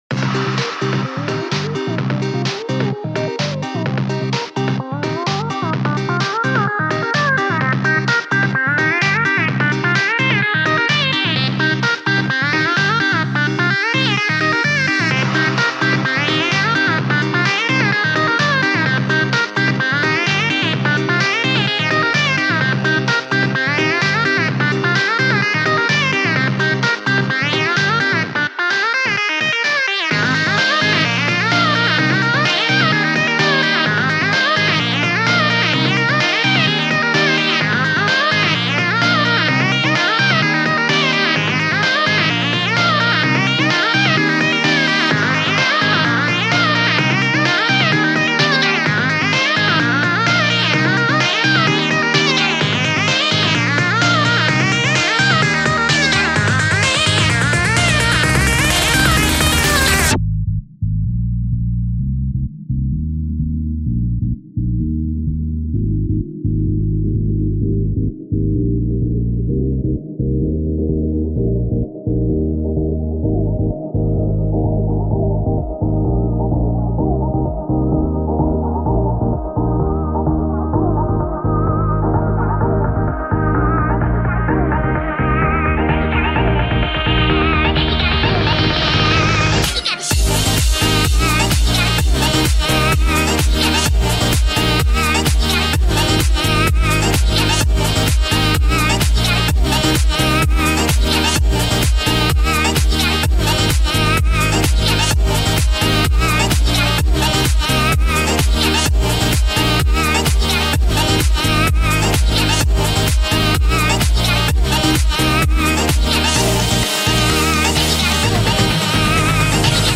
Today, I'm coming at ya with a french house-esque track